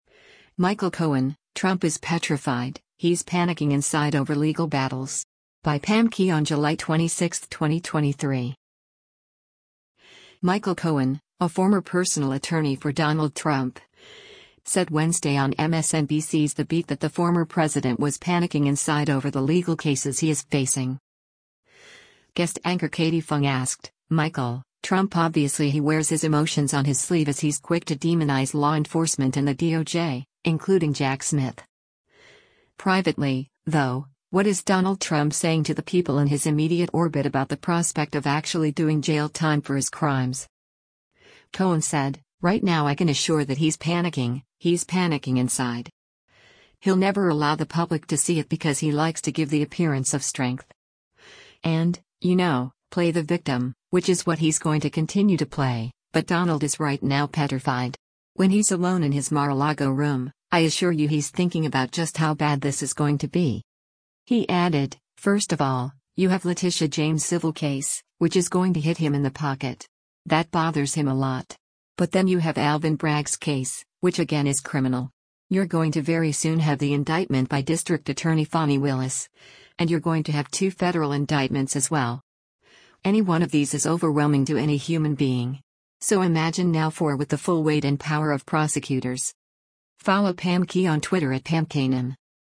Michael Cohen, a former personal attorney for Donald Trump, said Wednesday on MSNBC’s “The Beat” that the former president was “panicking inside” over the legal cases he is facing.